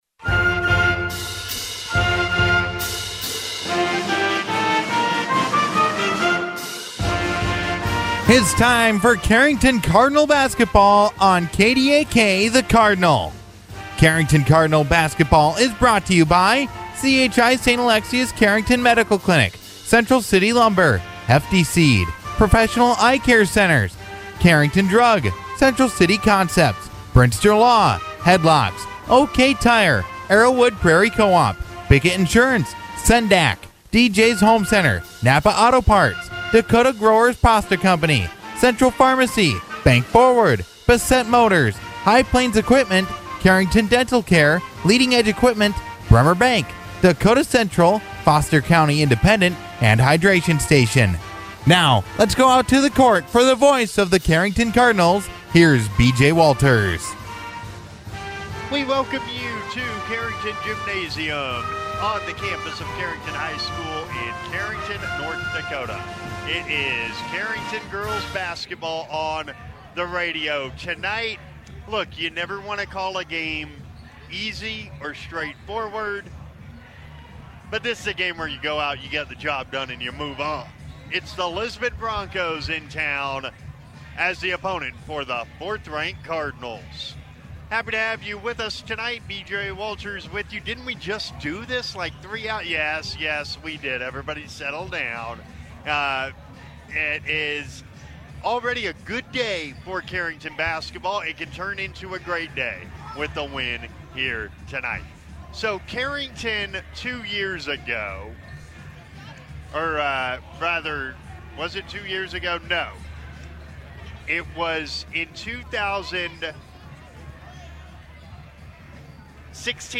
FULL GAME ARCHIVE